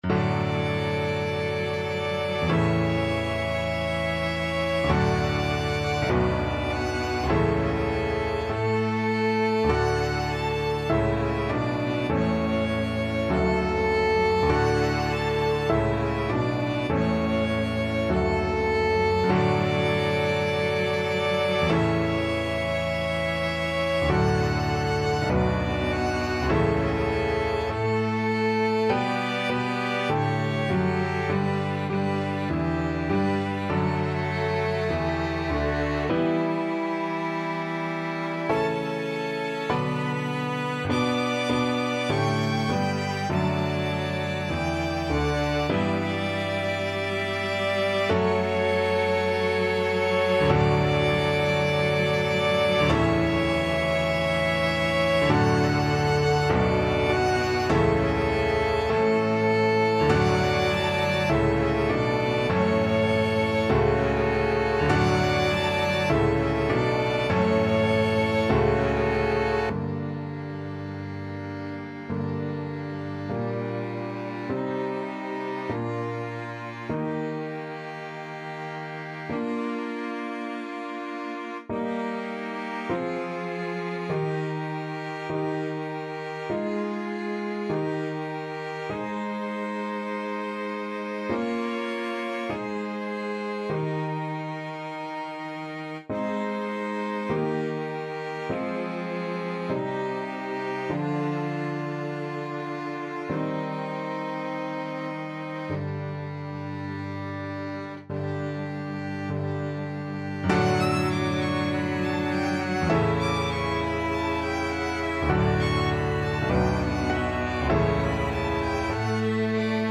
Piano Quartet version
(2vn, vc, pn)
Violin 1Violin 2CelloPiano
4/4 (View more 4/4 Music)
Classical (View more Classical Piano Quartet Music)